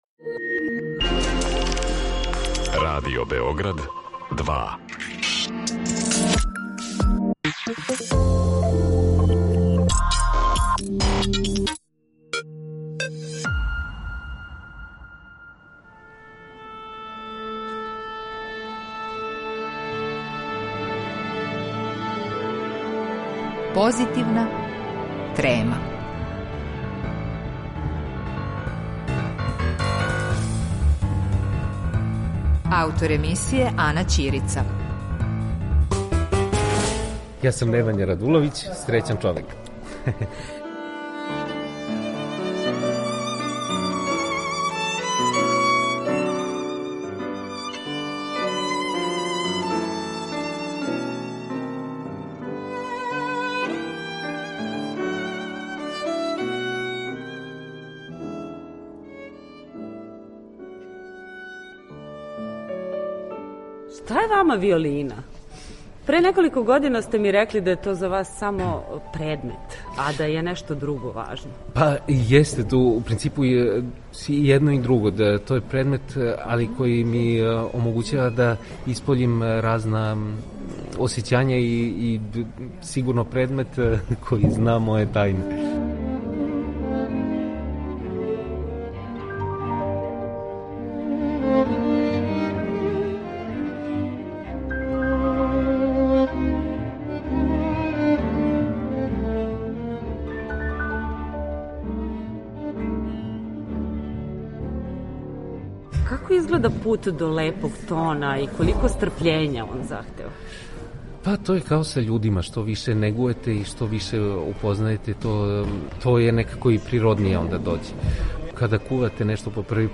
За Позитивну трему Радио Београда 2, Немања Радуловић је говорио 4. 2. 2022. године, пред концерт са оркестром Београдске филхармоније и диригентом Данијелом Рајскином.